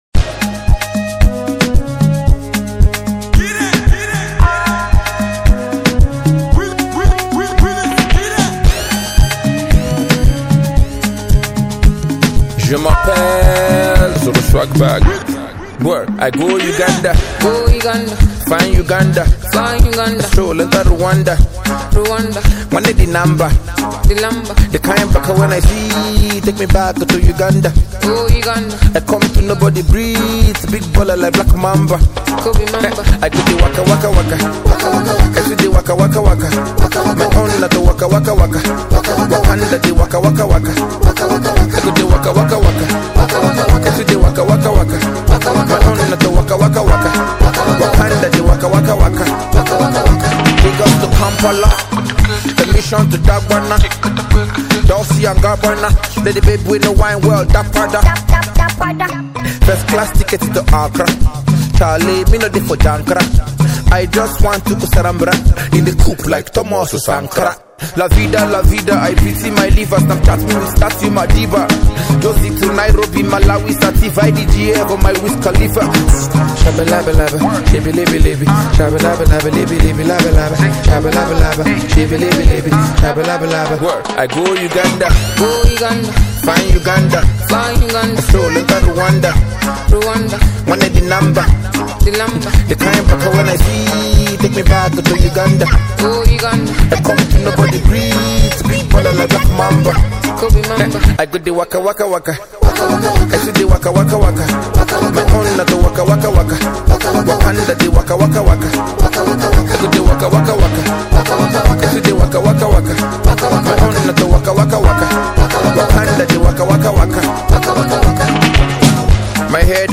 Nigerian indeginous rapper